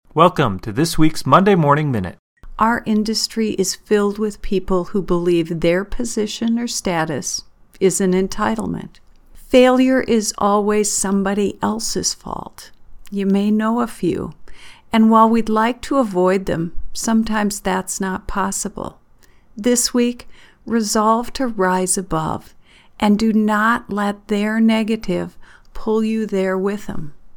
Studio version: